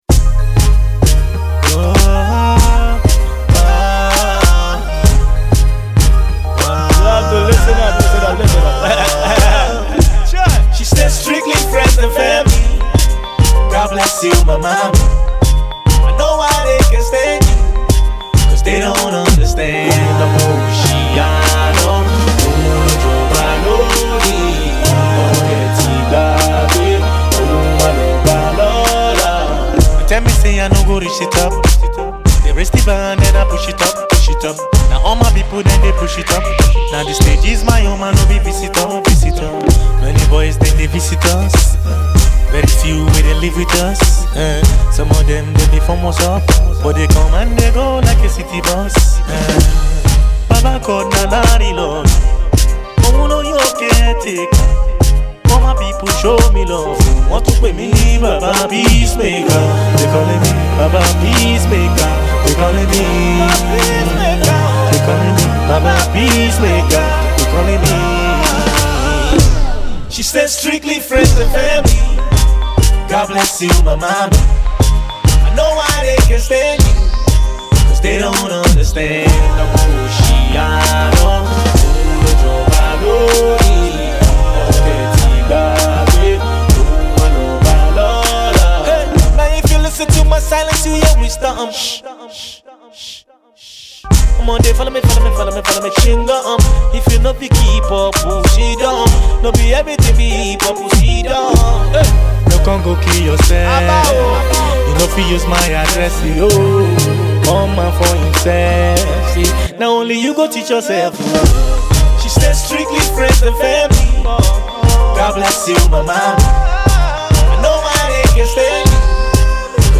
Alternative Pop